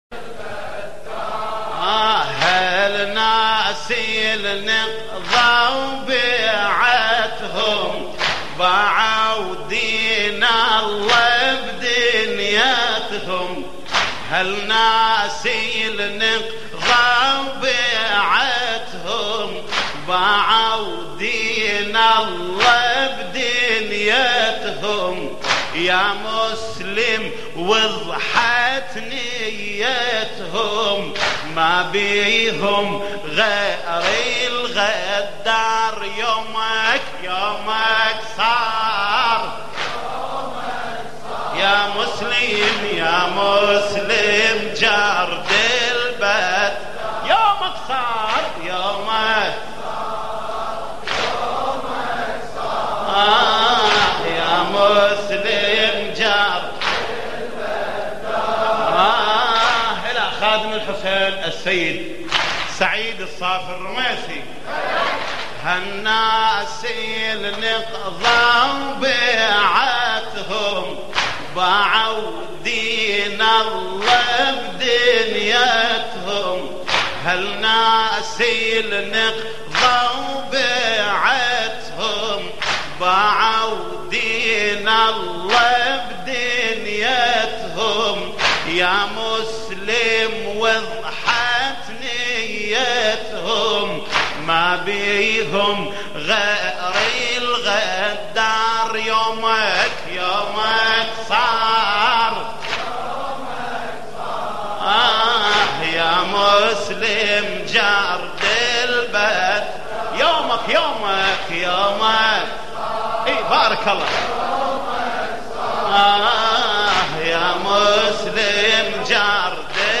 ملف صوتی يومك صار يومك صار يامسلم جرد البتار بصوت جليل الكربلائي
لطميات قديمة